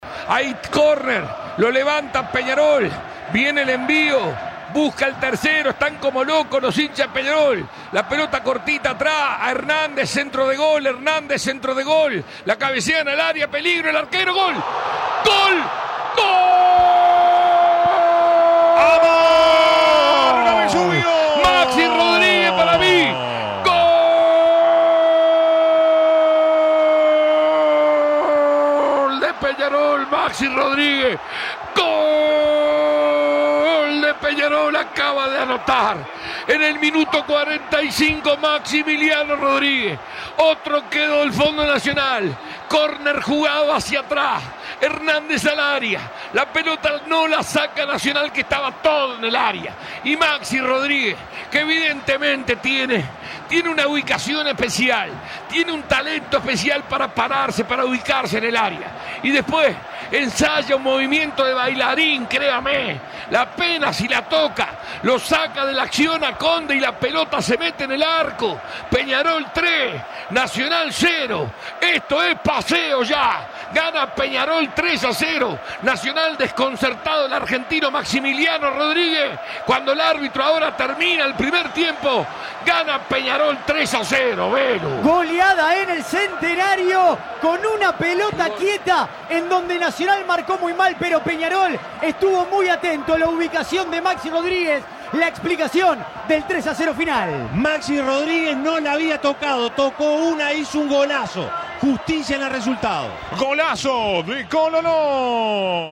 Escuchá los goles relatados por Alberto Sonsol.